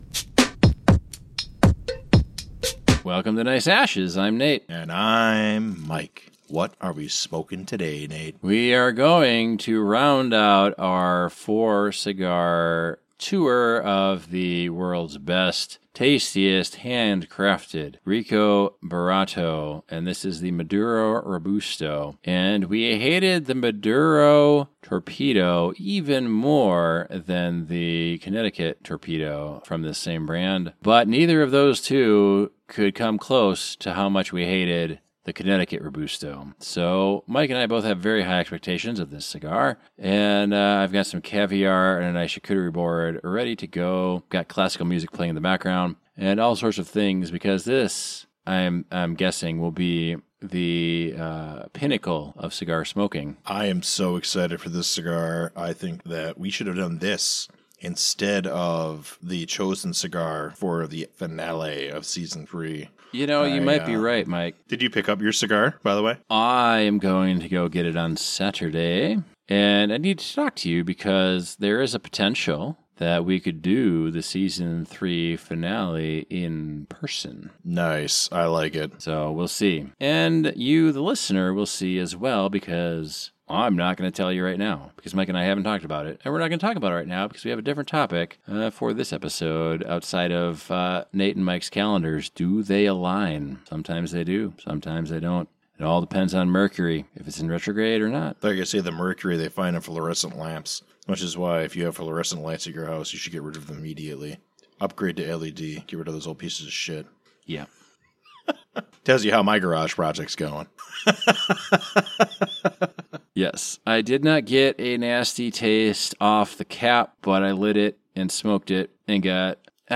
Today, they tackle the Maduro Robusto, which leads to plenty of laughter and strong opinions, as the duo recount their previous experiences with the brand, expressing just how much they loathe the previous cigars they've tried, including the Connecticut series.